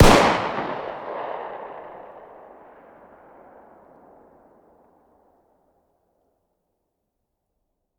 fire-dist-40sw-pistol-ext-04.ogg